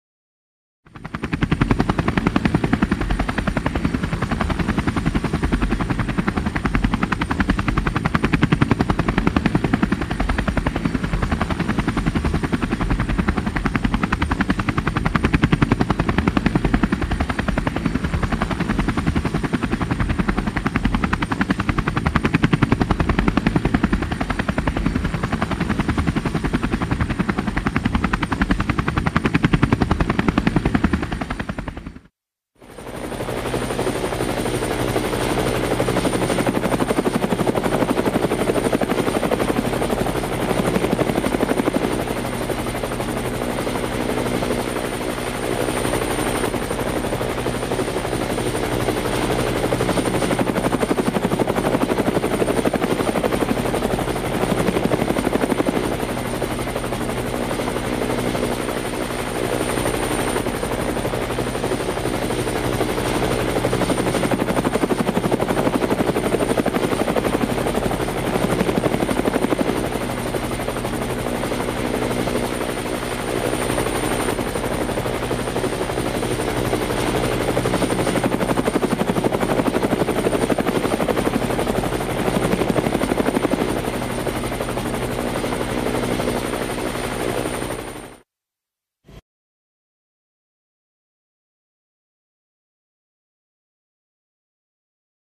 دانلود صدای هلیکوپتر 2 از ساعد نیوز با لینک مستقیم و کیفیت بالا
جلوه های صوتی